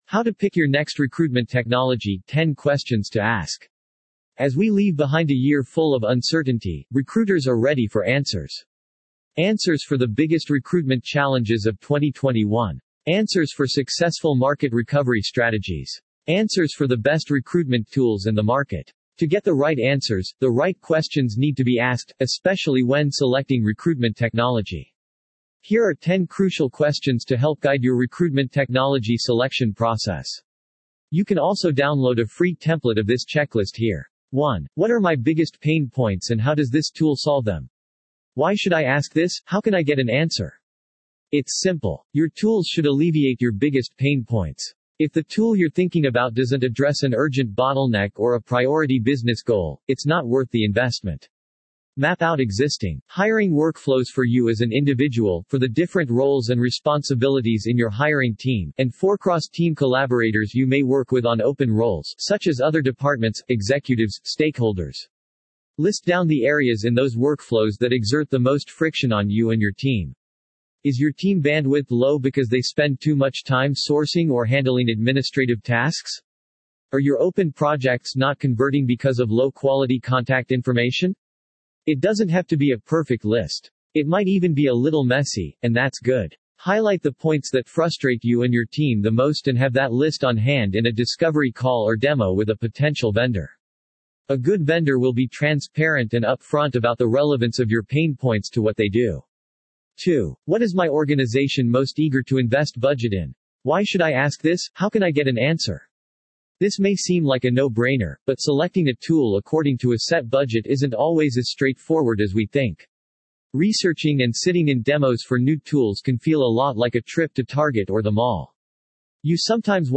You can use this audio player to convert website page content into human-like speech. 11:11 00:00 / 14:00 1.0X 2.0X 1.75X 1.5X 1.25X 1.0X 0.75X 0.5X As we leave behind a year full of uncertainty, recruiters are ready for answers.